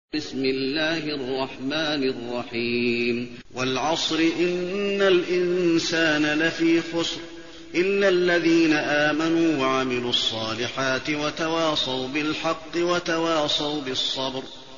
المكان: المسجد النبوي العصر